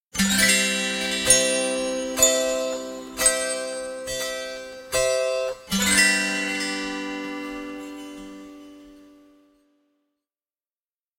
Звук магии и чудес